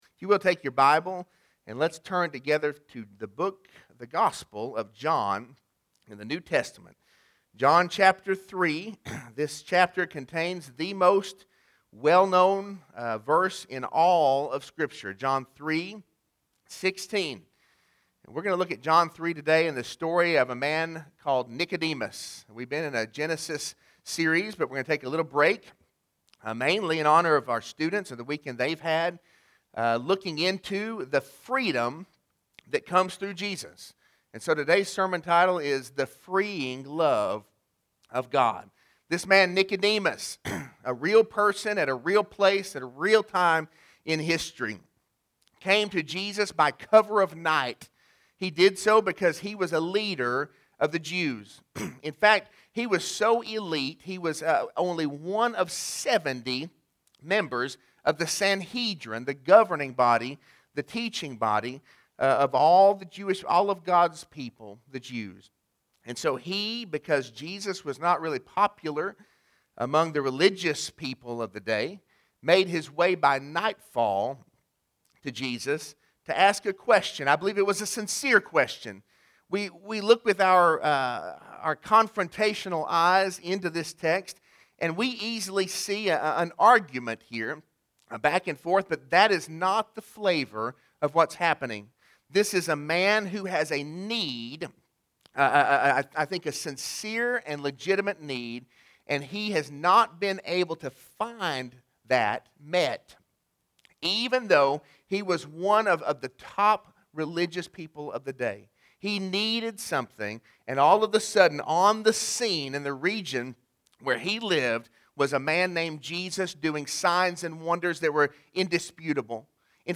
Sermon-3-7-21.mp3